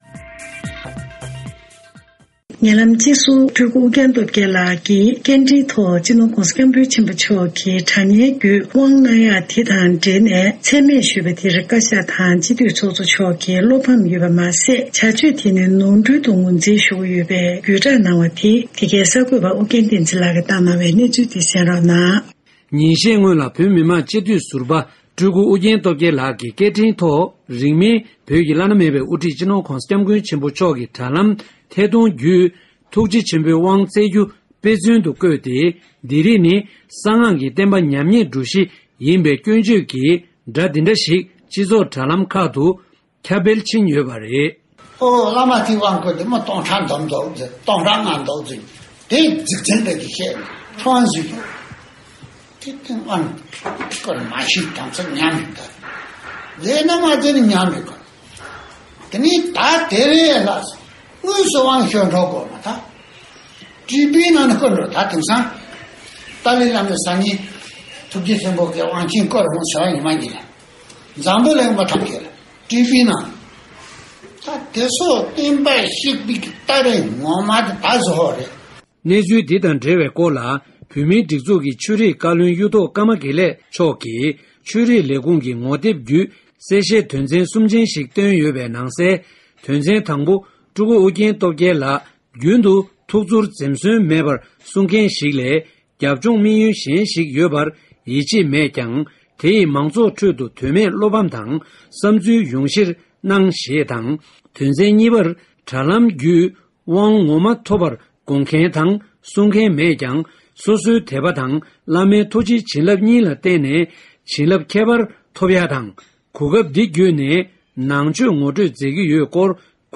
ཞིབ་ཕྲ་ས་གནས་གསར་འགོད་པས་བཏང་བའི་གནས་ཚུལ་ལ་གསན་རོགས།
སྒྲ་ལྡན་གསར་འགྱུར།